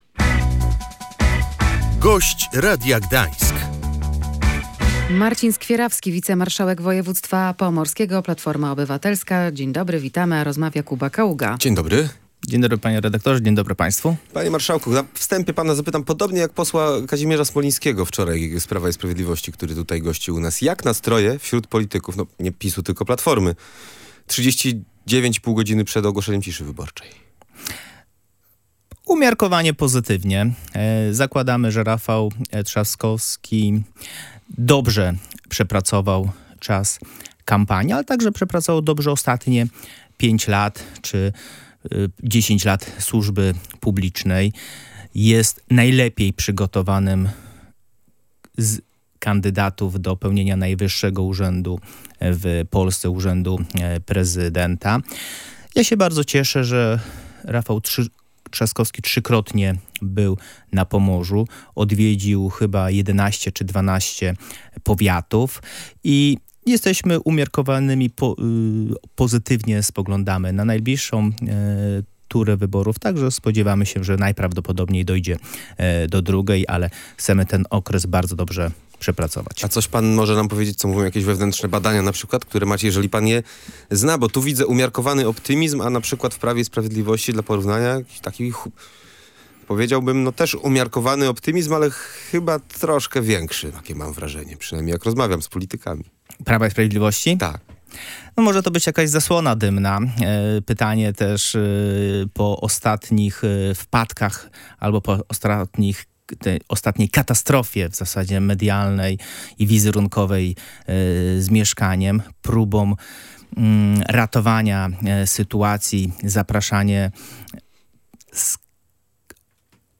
Platforma Obywatelska jest umiarkowanym optymistą w sprawie wyborów prezydenckich – mówił w Radiu Gdańsk wicemarszałek województwa Marcin Skwierawski.